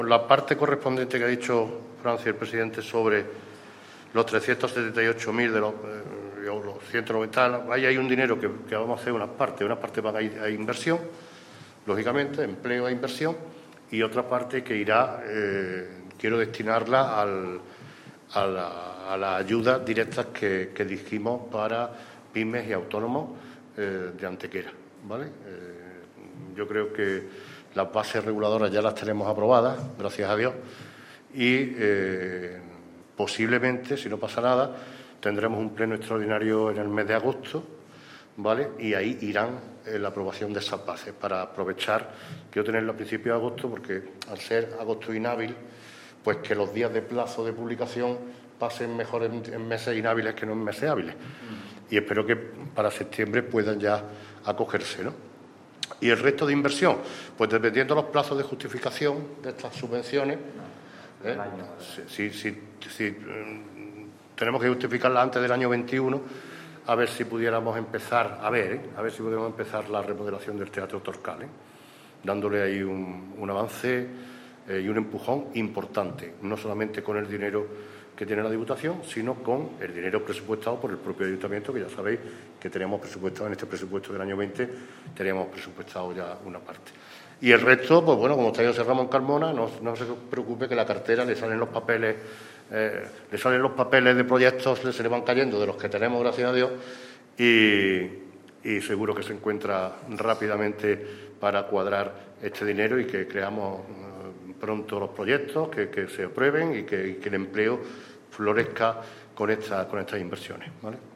Así lo ha anunciado este mediodía en el Ayuntamiento de Antequera el presidente de la Diputación, Francisco Salado, en una rueda de prensa en la que ha estado acompañado por el alcalde de Antequera, Manolo Barón, así como por el concejal antequerano y a su vez diputado provincial Juan Álvarez.
Cortes de voz